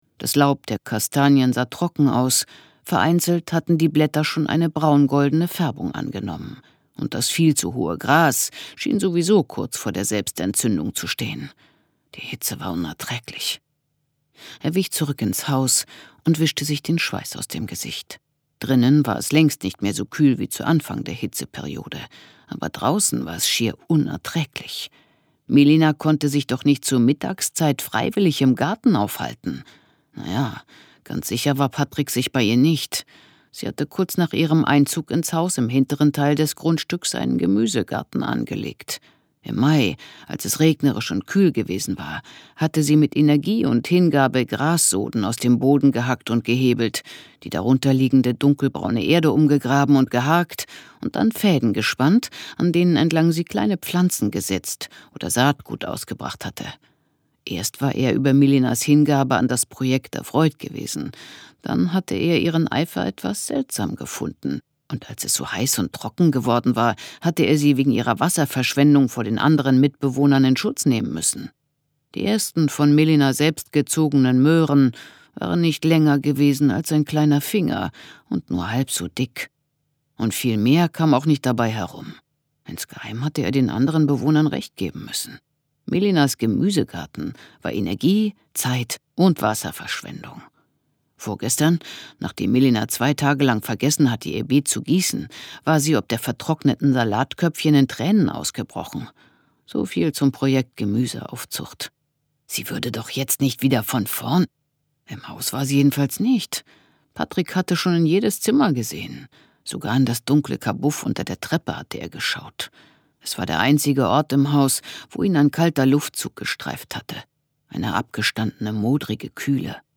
Pia Korittkis achter Fall. Ungekürzt.